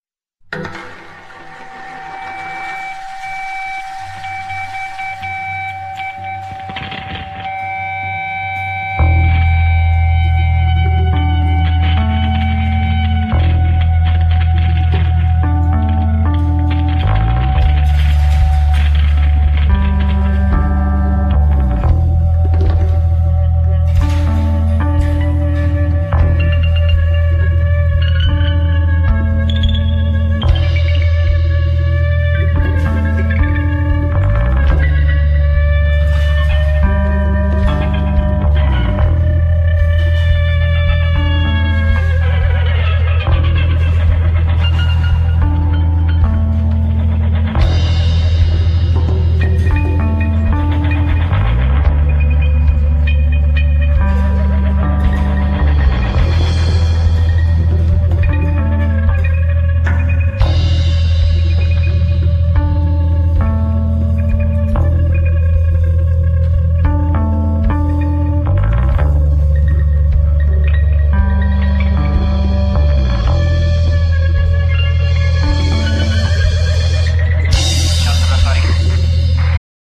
Genere : Wave post-punk